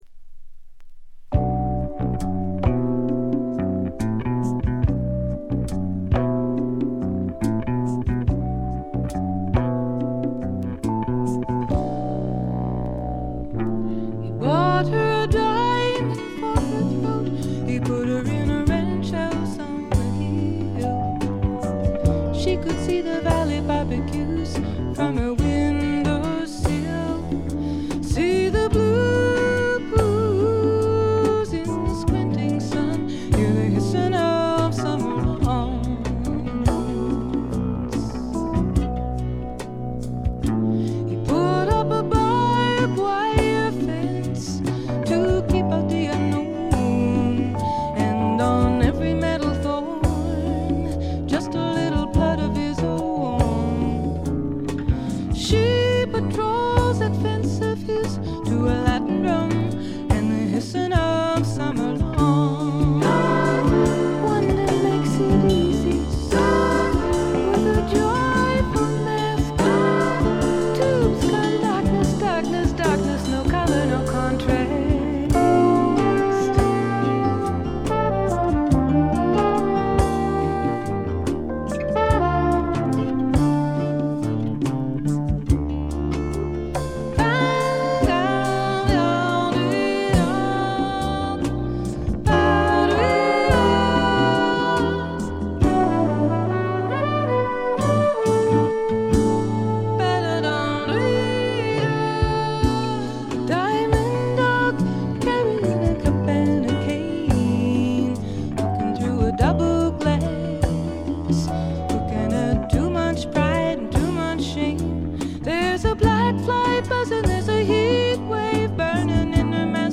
ディスク:部分試聴ですがほとんどノイズ感無し。極めて良好に鑑賞できると思います。実際の音源を参考にしてください。
ここからが本格的なジャズ／フュージョン路線ということでフォーキーぽさは完全になくなりました。
女性シンガーソングライター名作。
試聴曲は現品からの取り込み音源です。